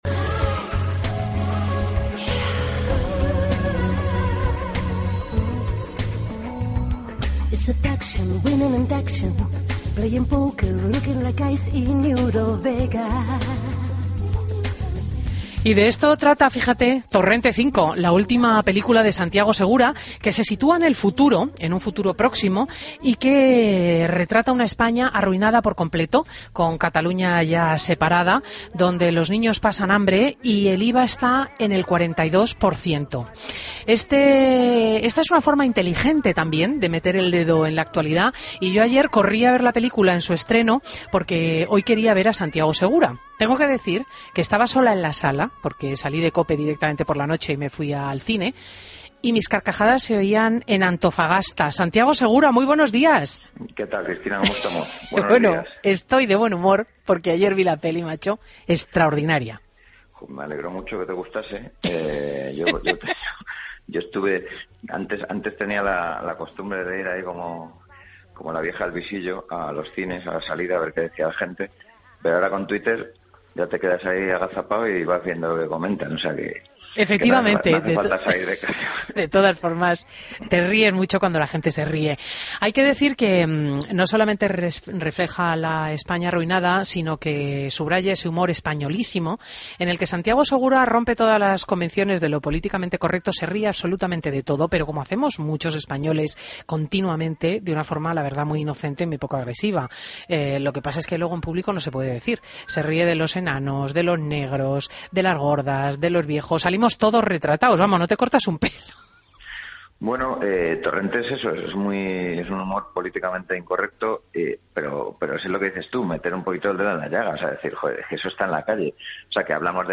Entrevista a Santiago Segura en Fin de Semana COPE